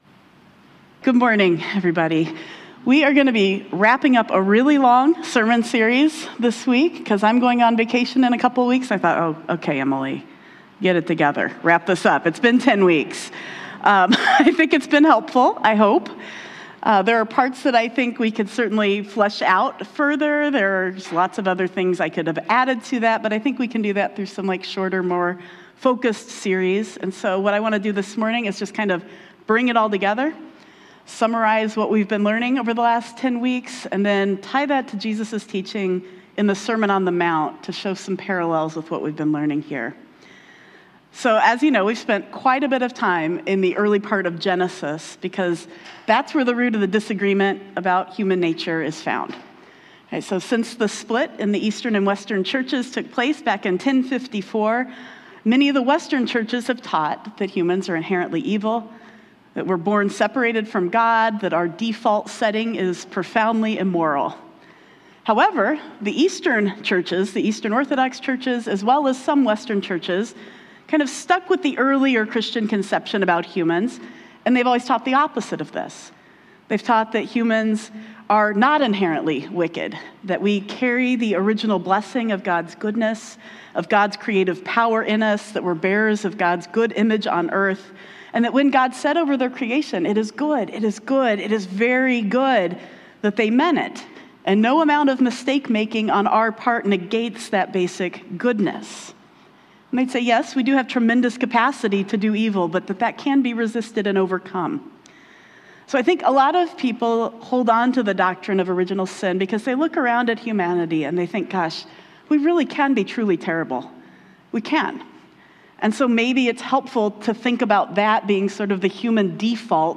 This week we wrap up our sermon series on Original Blessing by summarizing what we’ve learned and looking at it in light of the Sermon on the Mount.